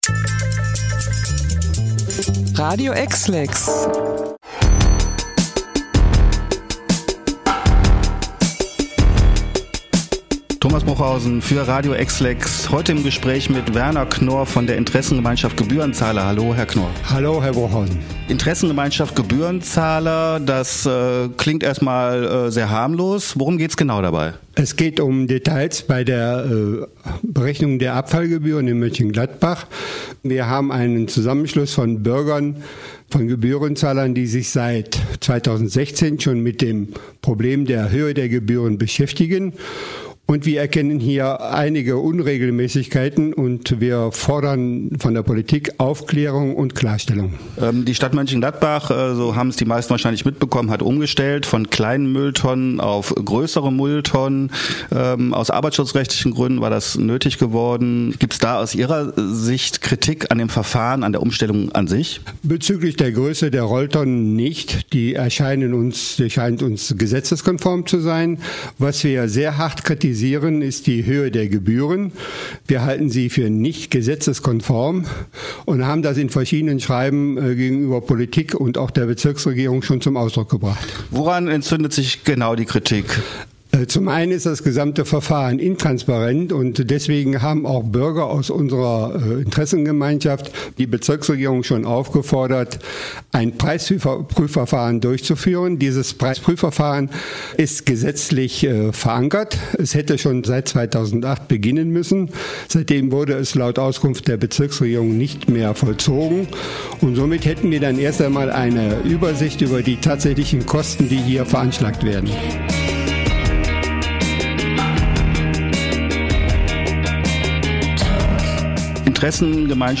Interview-IG-Gebührenzahler-TB.mp3